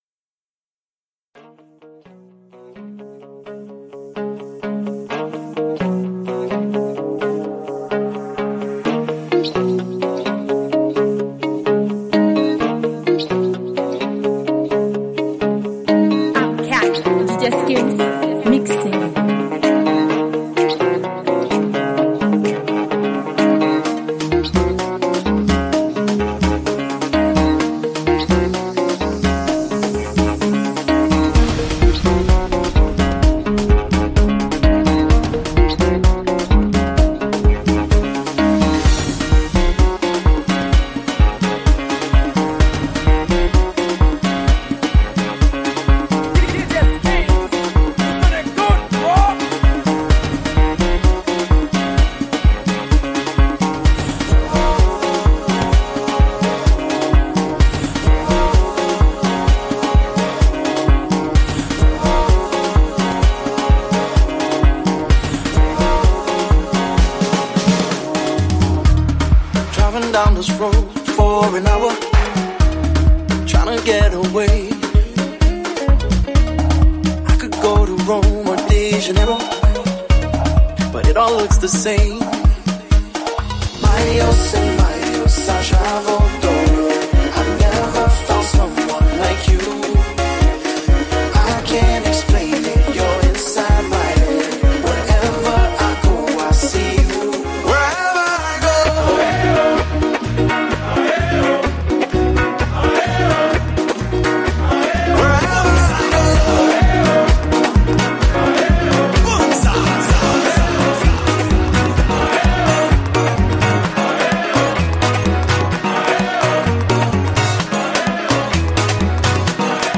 Genre: MIX.